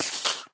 minecraft / sounds / mob / creeper / say4.ogg